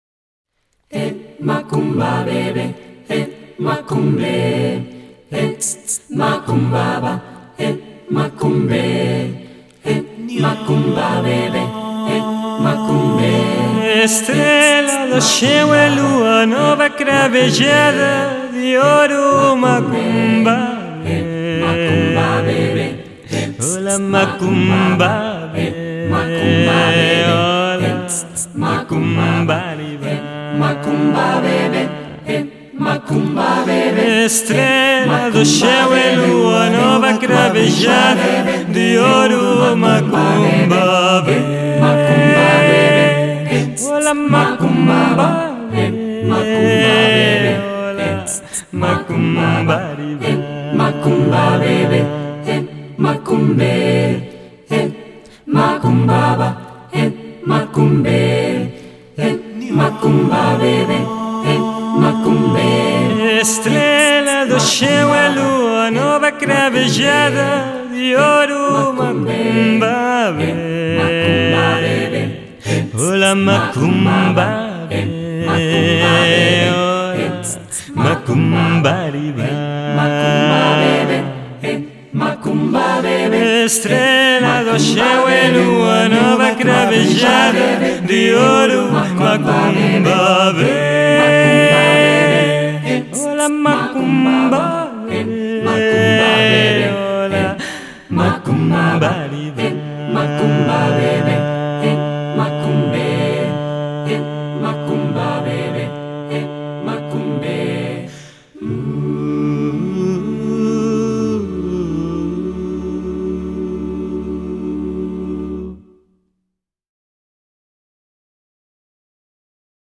a vocal band
Popular brasilera